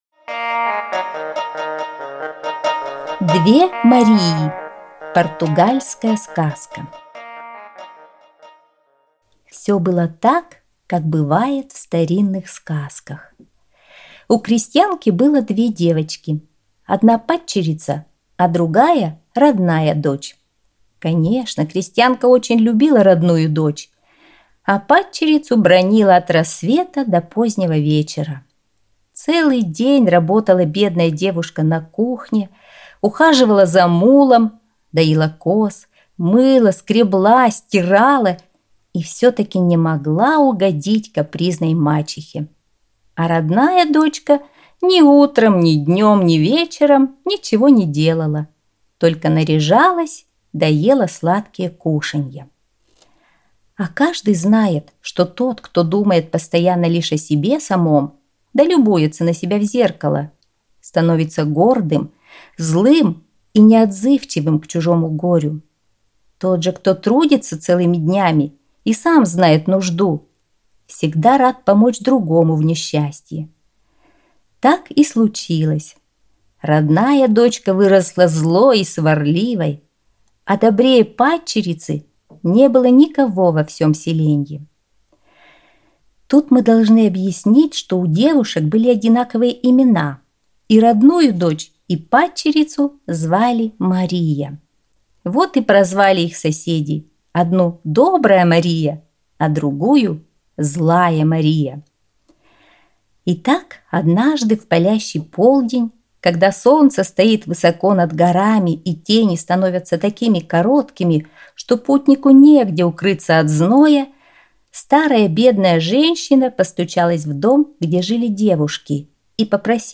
Две Марии - португальская аудиосказка - слушать онлайн